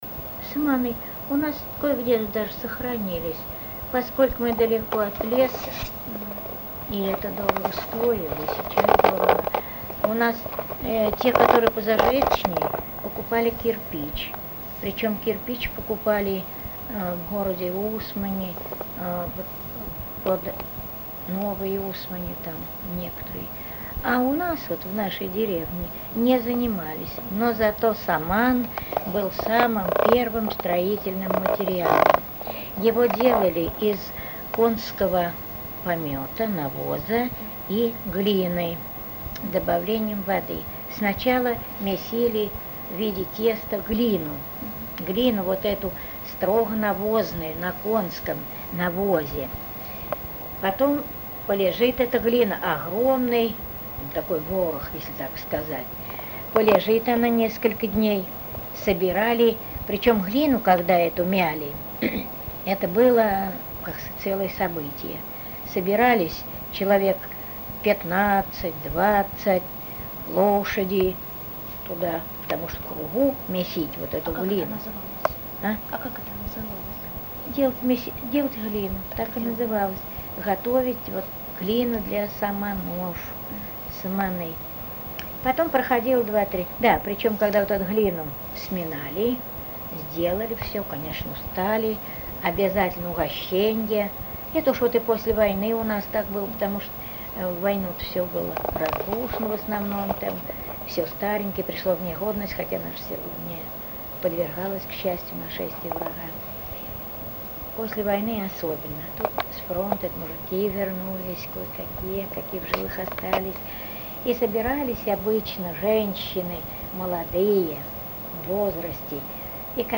Место фиксации: Воронежская область, Верхнехавский район, село Верхняя Луговатка